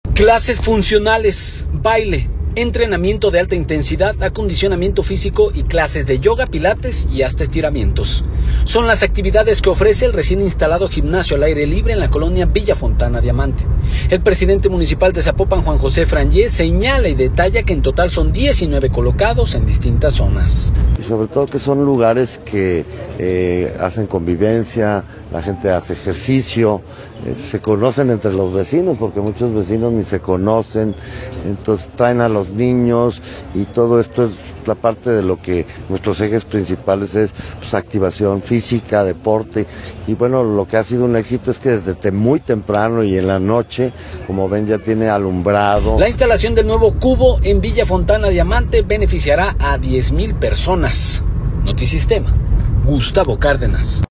Son las actividades que ofrece el recién instalado gimnasio al aire libre en la colonia Villa Fontana Diamante. El presidente municipal de Zapopan, Juan José Frangie, señala y detalla que en total son 19 colocados en distintas zonas.